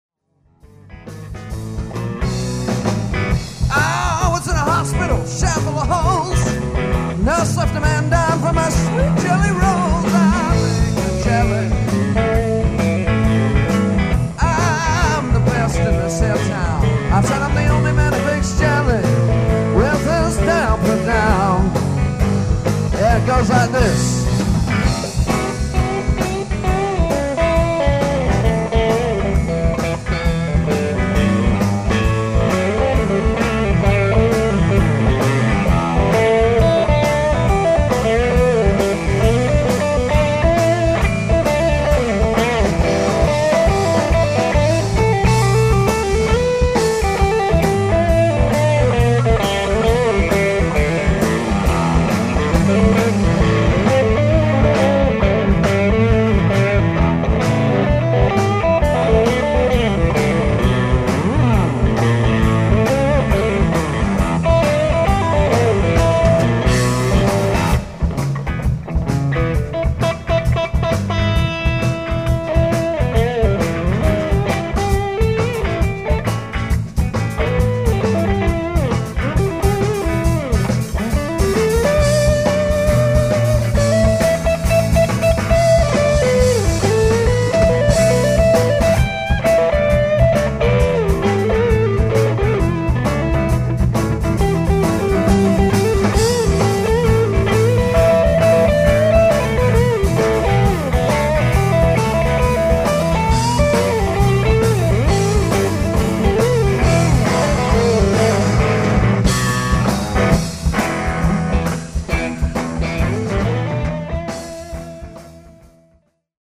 Vintage Reverb 30 Head
Blues Solo